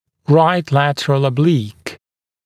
[raɪt ‘lætərəl ə’bliːk][райт ‘лэтэрэл э’бли:к]правый боковой наклонный снимок